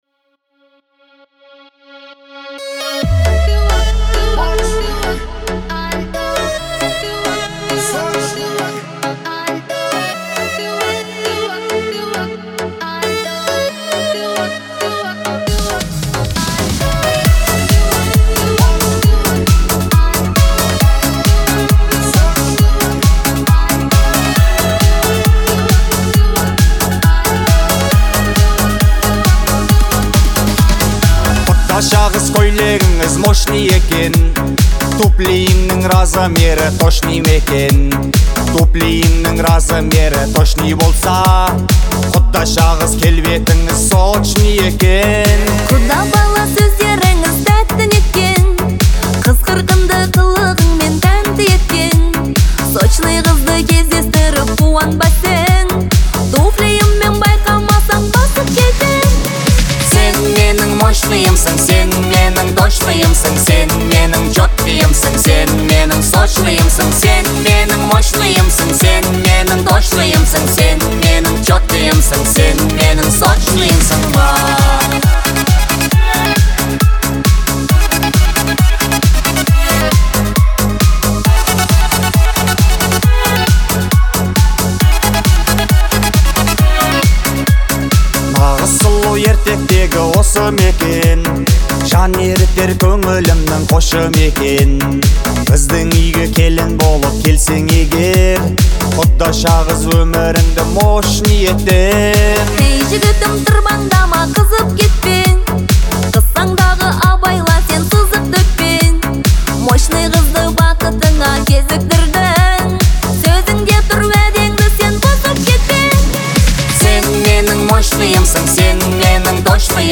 • Категория: Казахские песни